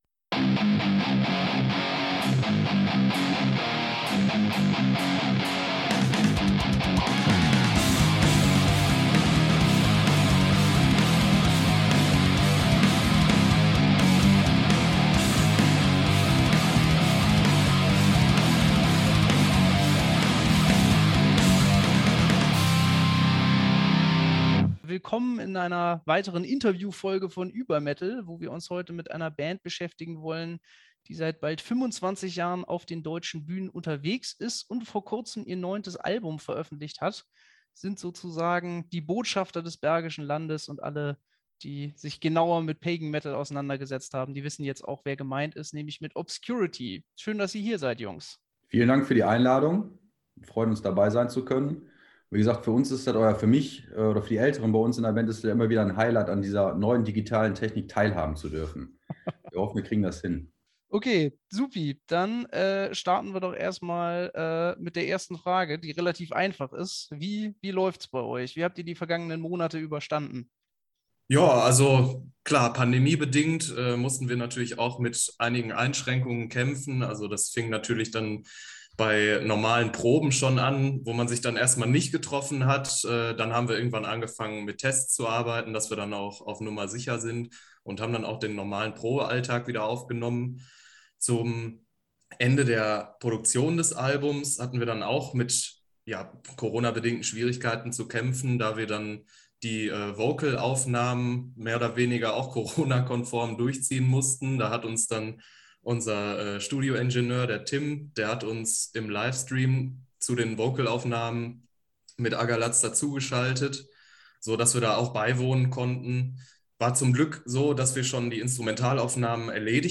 Diesmal kommen die entsprechenden Interview-Gäste allerdings nicht aus dem hohen Norden, sondern aus dem Bergischen Land, das sie...